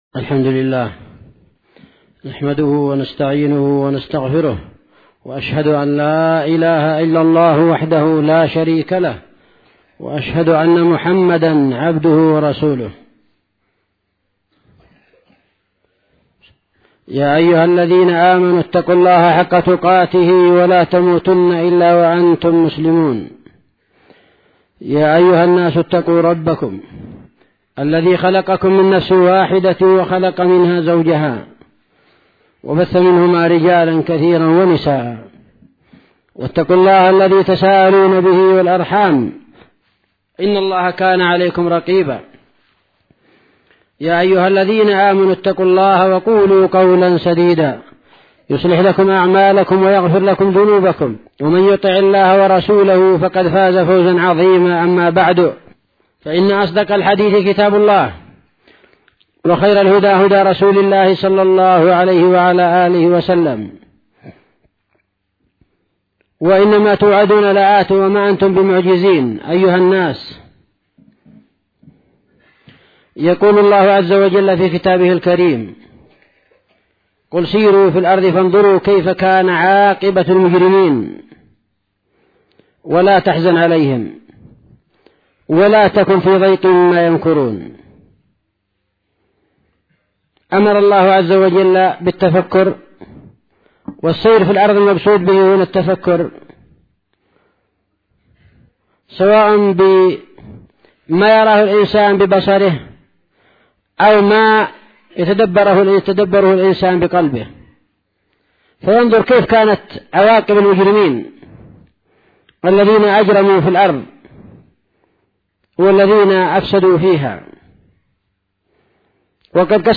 [وعيد الله عز وجل لأهل الإجرام بالهلاك والانتقام ] خطبة الجمعة (3 صفر